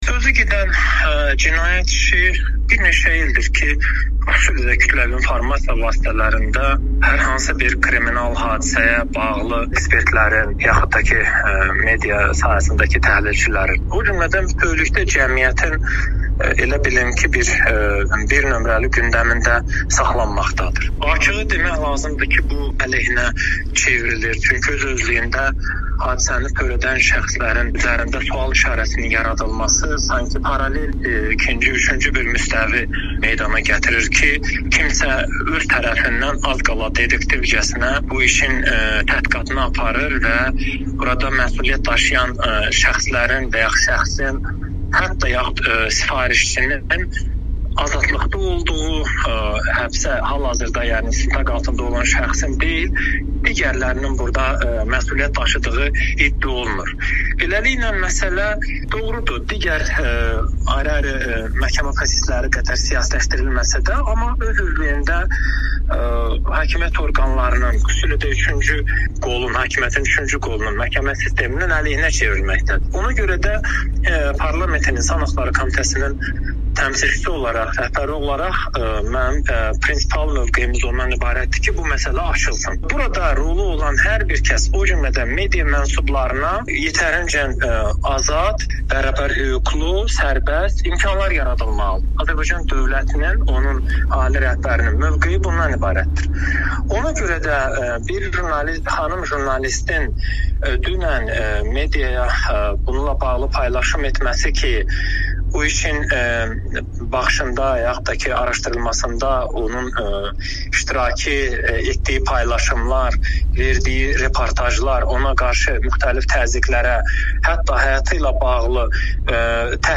Millət vəkili Zahid Orucun Amerikanın Səsinə müsahibəsi